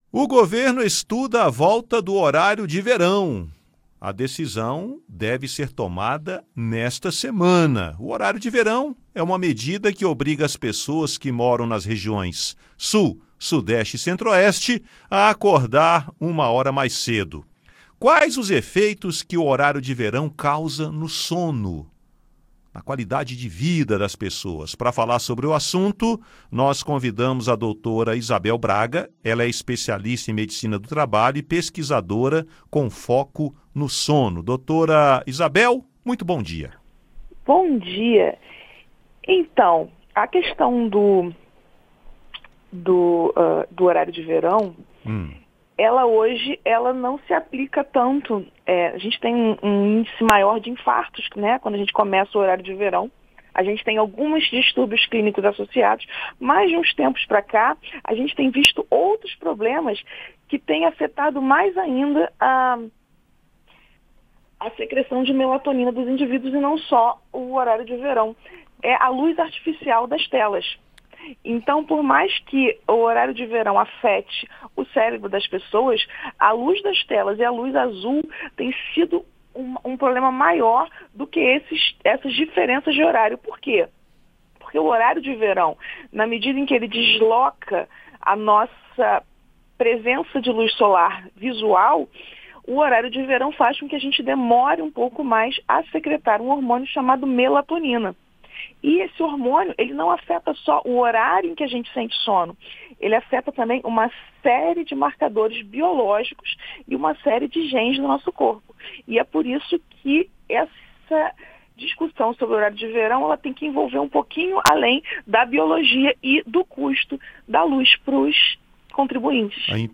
Médica fala sobre o impacto do horário de verão no sono de uma pessoa